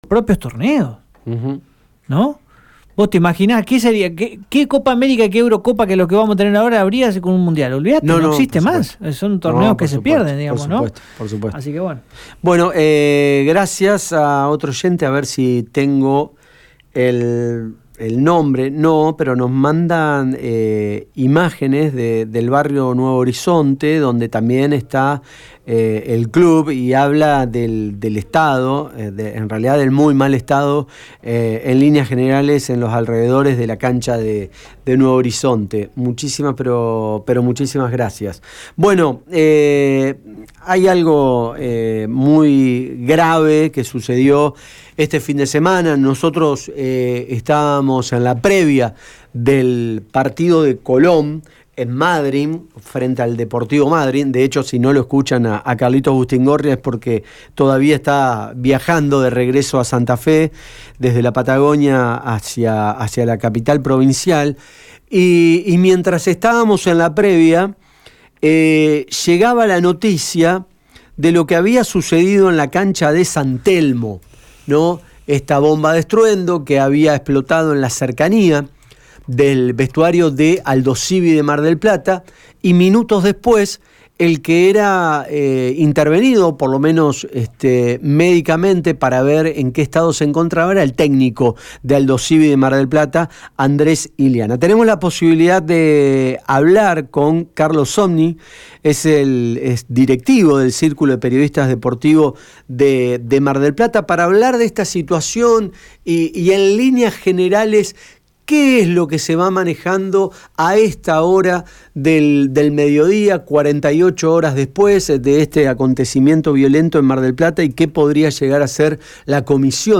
Escuchá la palabra del periodista